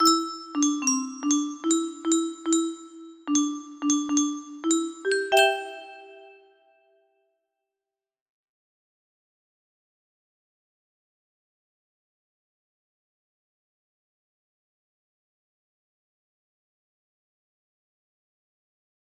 21004 music box melody